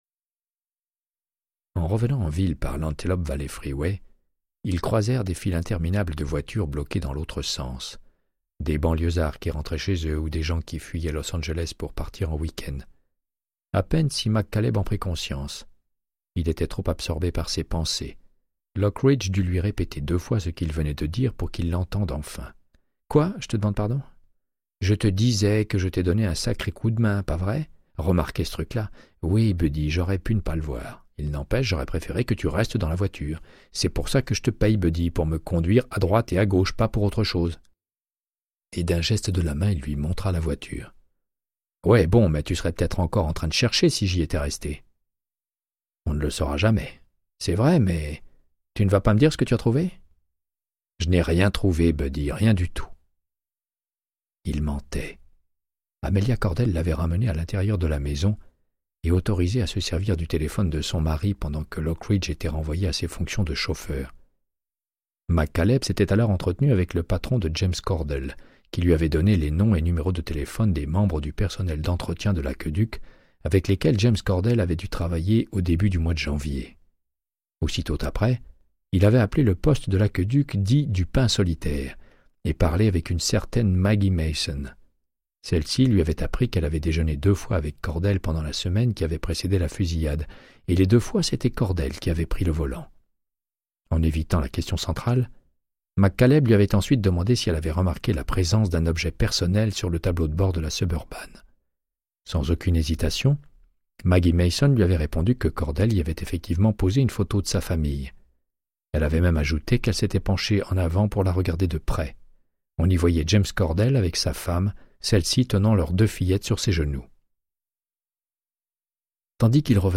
Audiobook = Créance de sang, de Michael Connellly - 85